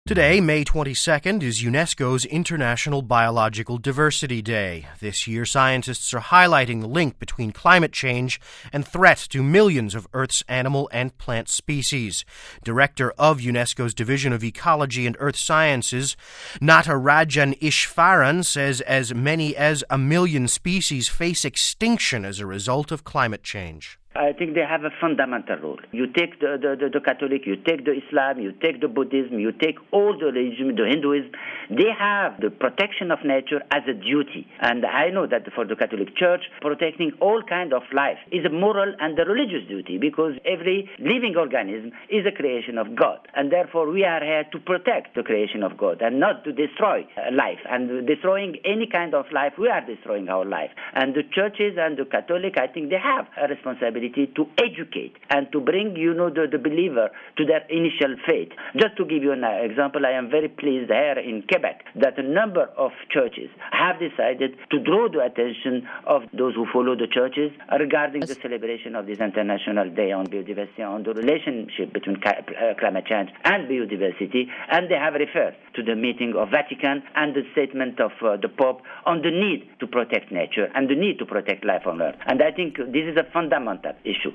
Home Archivio 2007-05-22 18:48:08 Tuesday is BioDiversity Day (22 May 07 - RV) May 22nd is UNESCO’S International Biological Diversity Day. Here's our report...